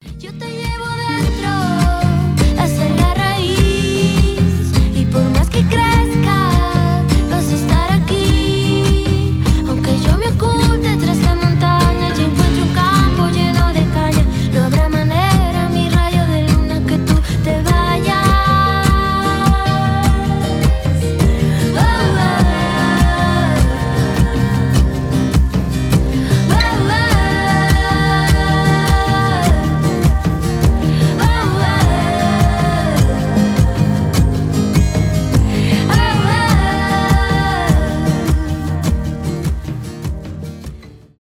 мексиканские , фолк , зарубежные , поп , испанские